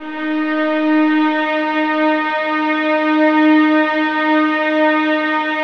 VIOLINS FN-R.wav